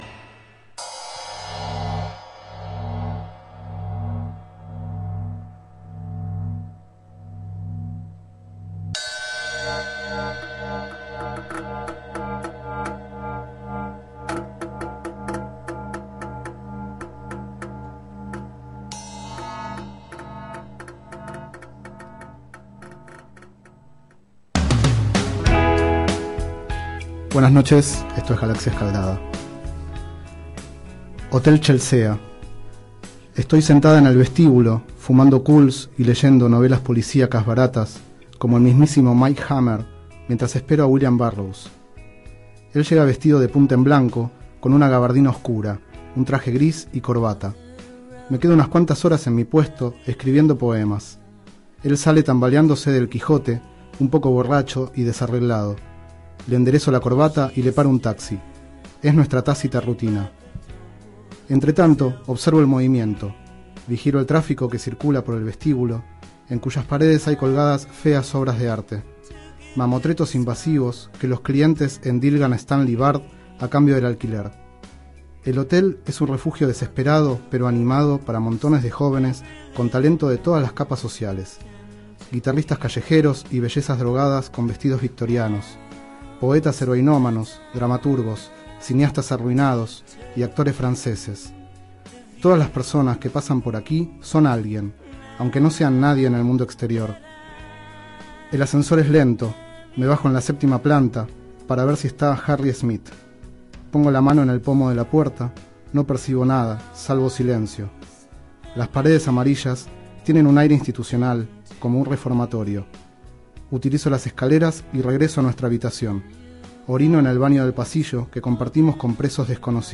Este es el 3º micro radial, emitido en el programa Enredados, de la Red de Cultura de Boedo, por FMBoedo, realizado el 13 de julio de 2010, sobre la reciente publicación del libro de memorias de Patti Smith, llamado Éramos unos niños.
Durante el micro se escucha Elegie , tema # 8 de su primer disco Horses (1975) y Mother Rose, tema # 2 de Trampin’ (2004).